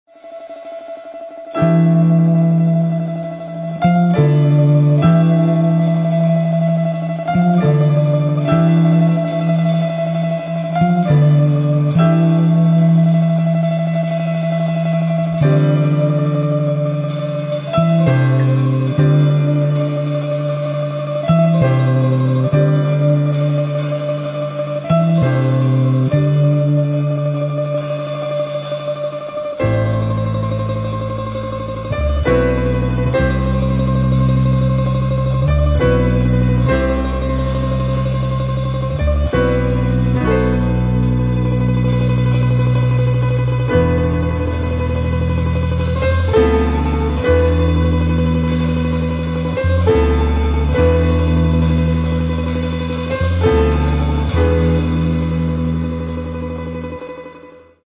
Каталог -> Джаз та навколо -> Етно-джаз та фольк
recorders, piano
acoustic guitar, domra, bayan
bass-guitar
vibraphone, china gongs, percussion, bongo, congo, drums
vocal, percussion